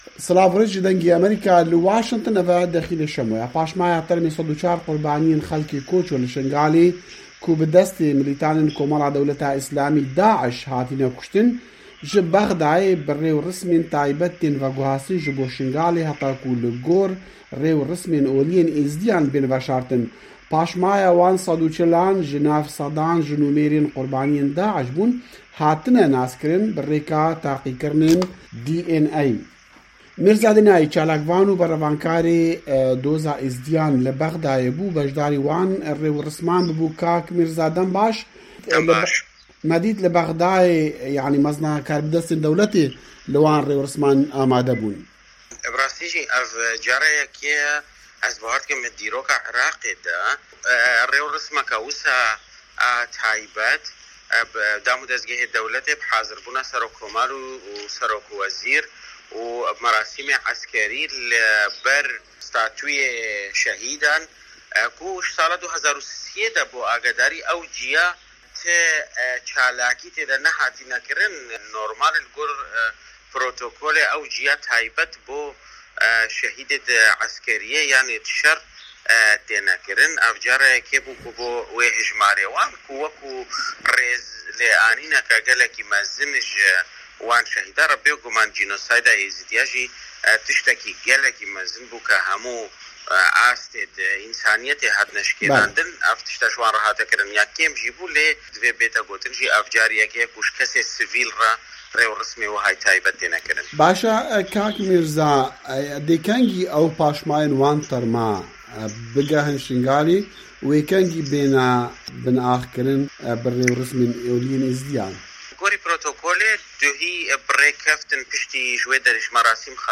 Di hevpeyvînekê de digel Dengê Amerîka VOA